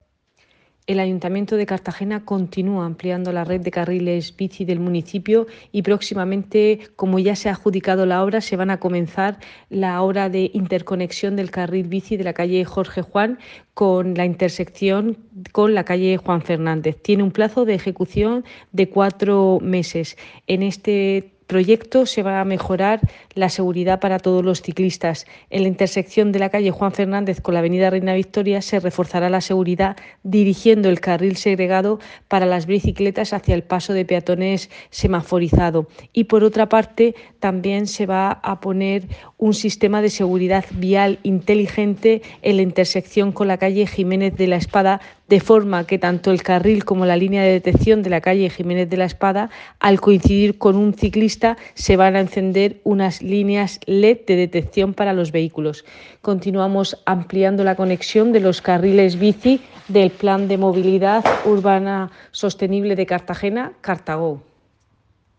Enlace a Declaraciones de Cristina Mora.